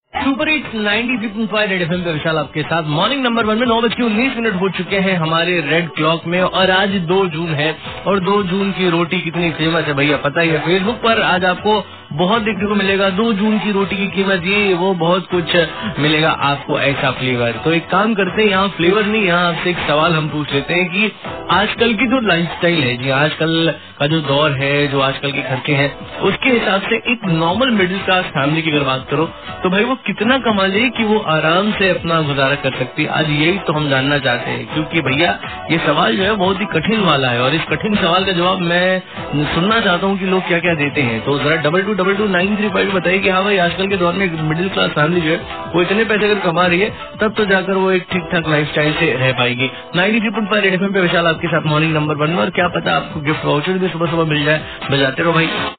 RJ TALKING ABOUT INTRESTING QUESTION